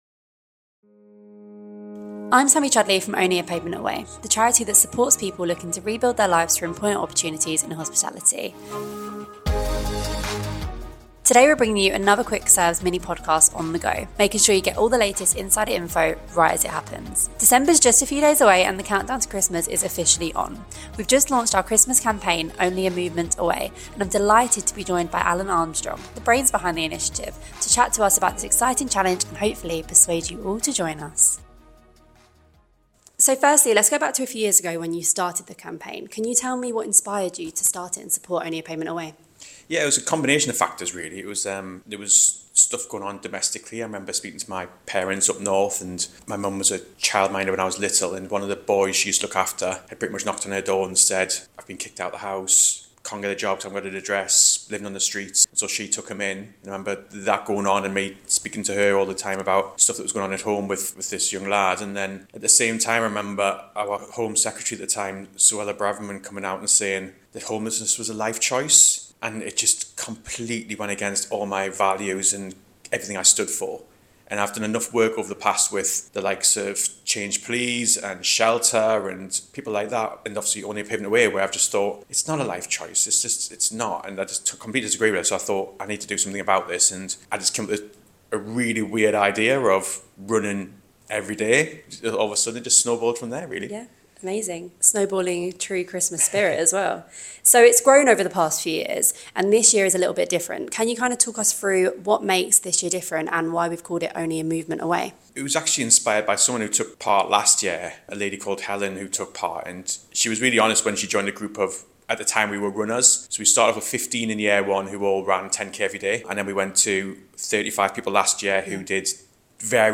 Quick Serves: The Only A Pavement Away Mini Podcast On-the-Go